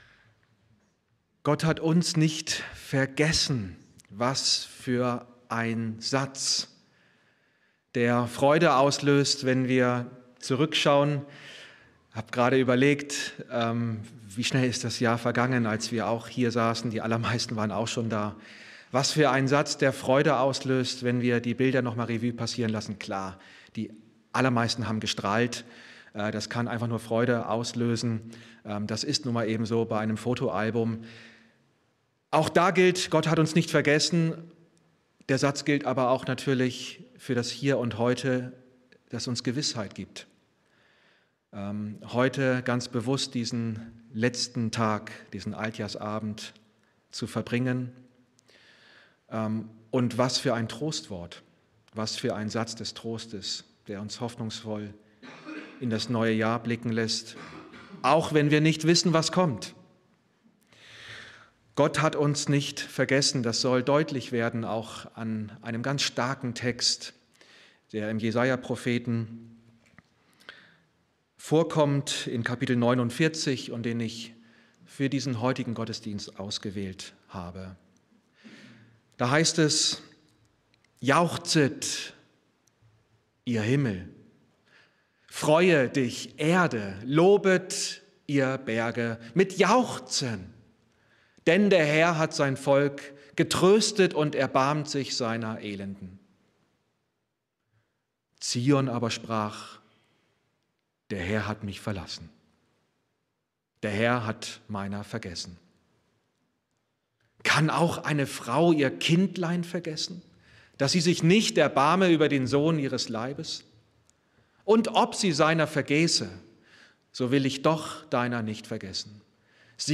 Predigten aus der Gemeinde in Bietigheim